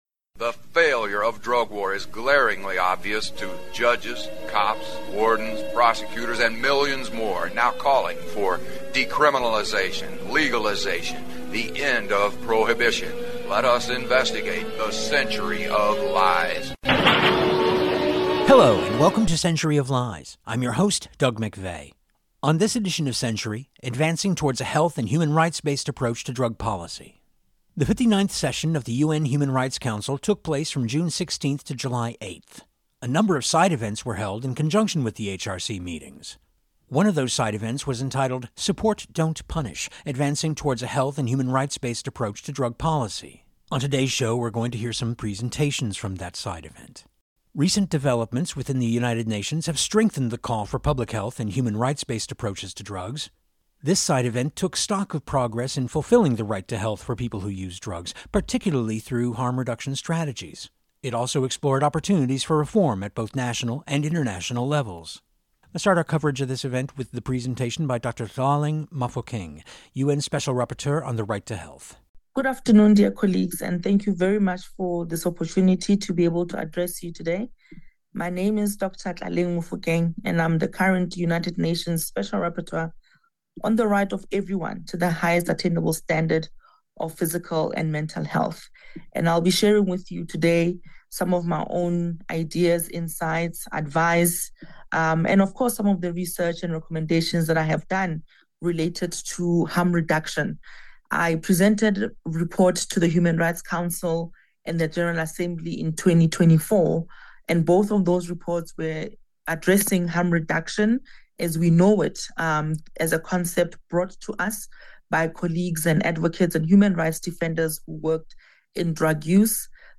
This week on Century we’ll hear portions of that side event